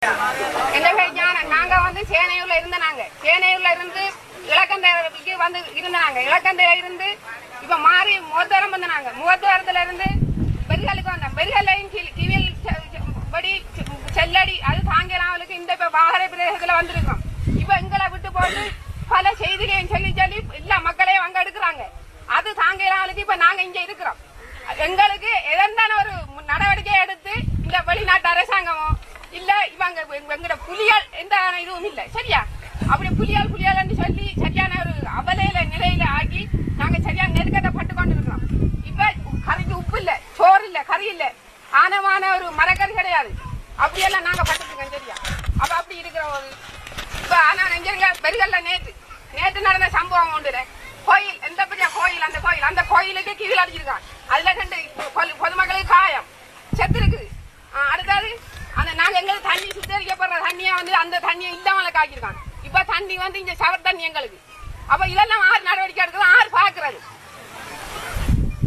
“I have been running from place to place for the last four months to escape shelling and Kfir attacks by the Sri Lankan Forces. The international community should do something to save our lives," an internally displaced woman told in Vaharai Saturday.